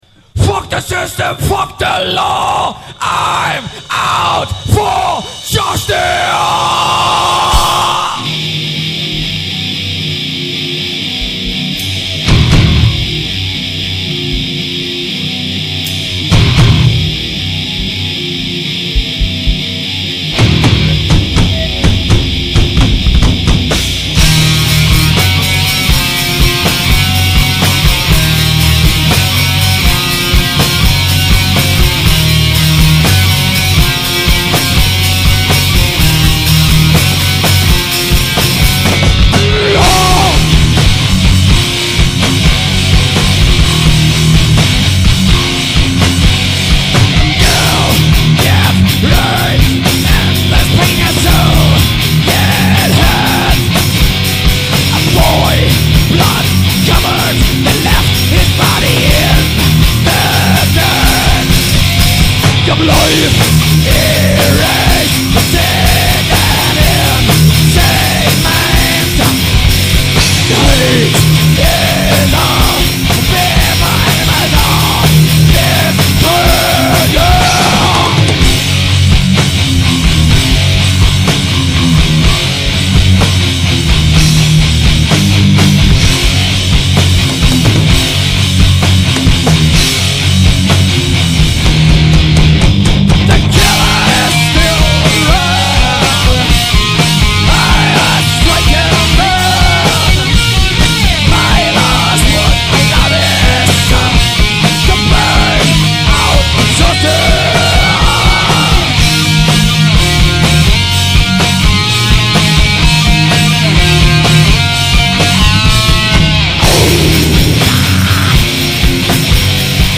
Unser roher, rauer Sound lässt sich nur schwer beschreiben.
Schweißtreibende, eingängige Riffs, mit einer portion Groove das ganze gepaart mit kompromisslosen, direkten Lyrics ergibt eine aggressive Mischung die durch eine explosive Bühnenshow noch unterstrichen wird!!!!!!!!
Shouts, Guitar
Bass
Drums, back Shouts
Bandkontest Fotogalerie: Demo Songs: never.mp3 out_for_justice.mp3 wrapped_in_flesh.mp3